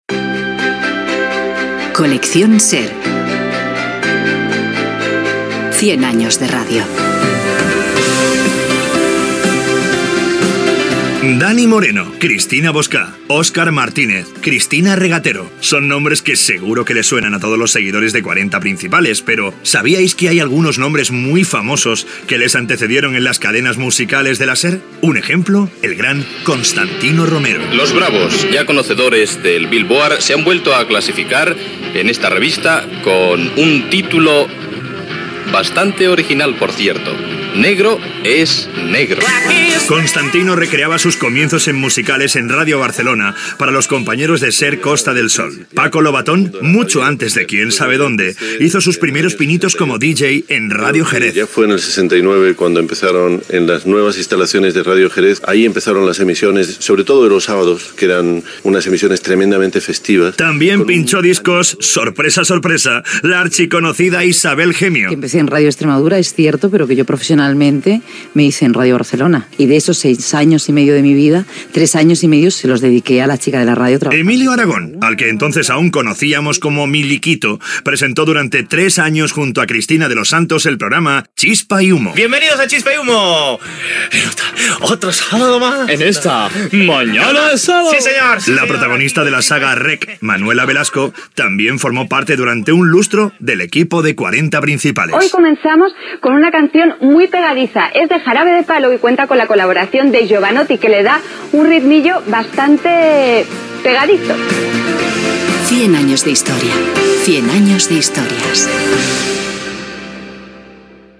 Careta del programa, comunicadors famosos que van començar a fer de presentadors a la ràdio musical, indicatiu dels 100 anys de ràdio